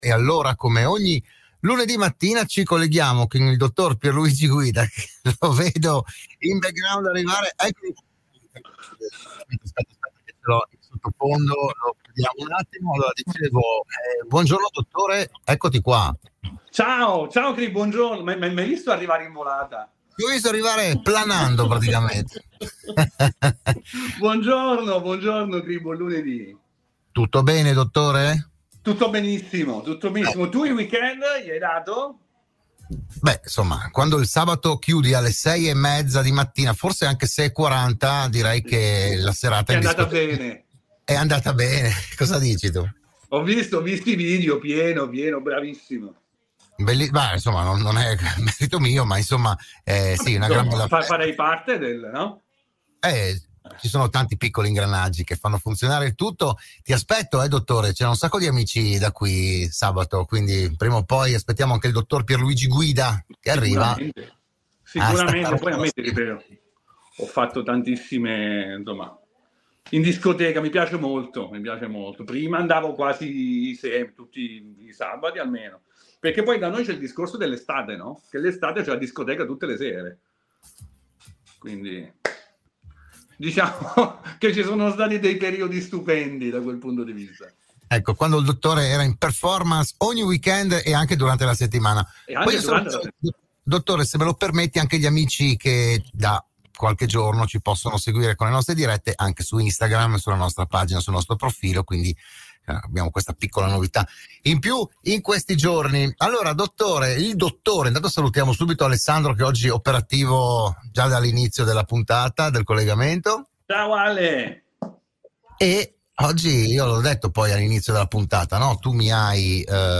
Nuova puntata per “Buongiorno Dottore”, il programma di prevenzione e medicina in onda ogni lunedì all’interno della trasmissione di Radio Studio Nord “RadioAttiva”.